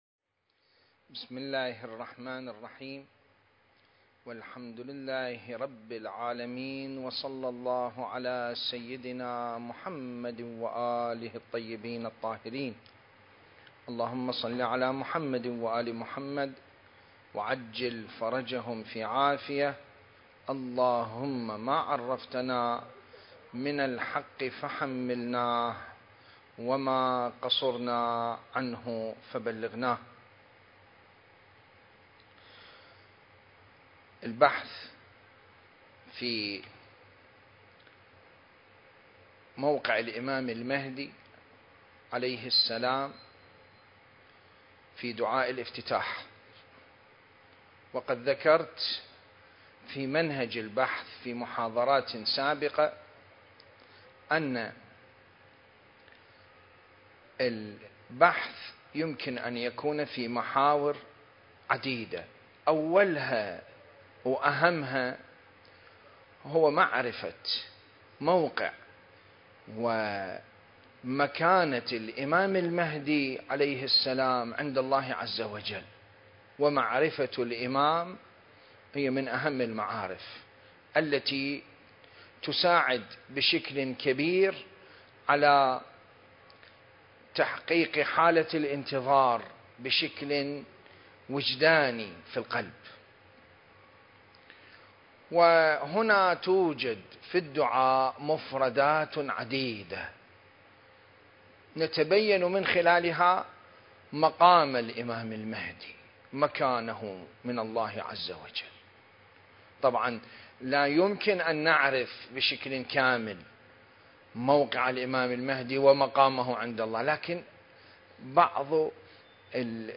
سلسلة: الإمام المهدي (عجّل الله فرجه) في دعاء الافتتاح (3) المكان: العتبة العلوية المقدسة التاريخ: 2021